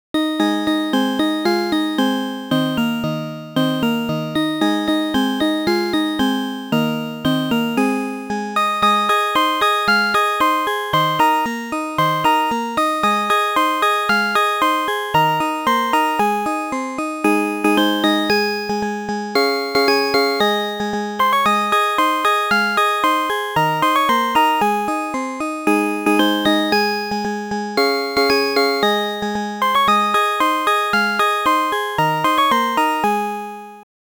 P1S8829はセイコーエプソン製メロディIC「SVM7570C/M」シリーズの音源を忠実に再現したシミュレーション用SF音源です。